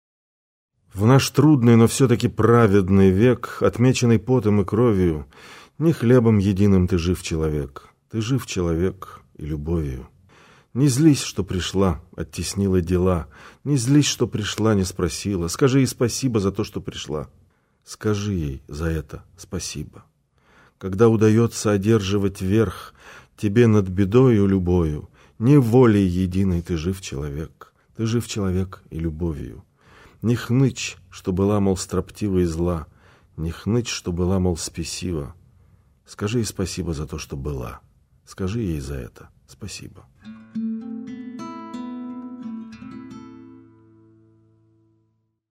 Леонид Алексеевич Филатов. Стихи (2009)(чит. Владимир Качан)